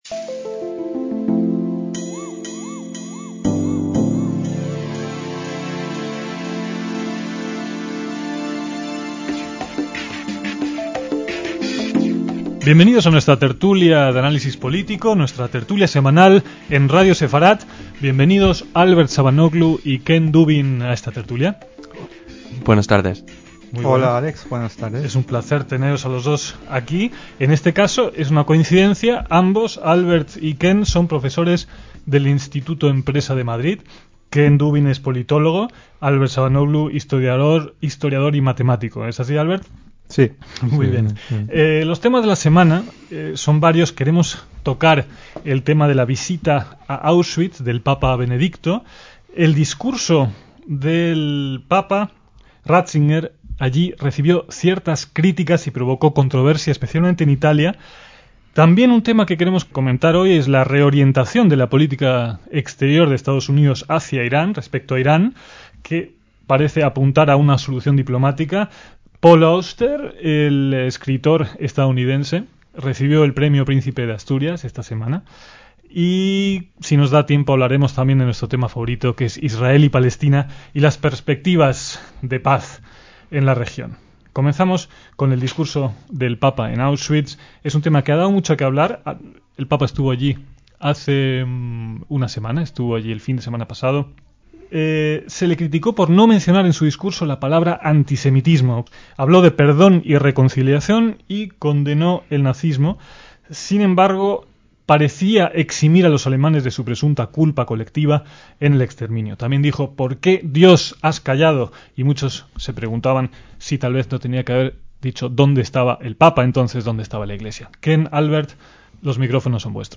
DECÍAMOS AYER (3/6/2006) - Los temas abordados en esta tertulia semanal de los primeros años de Radio Sefarad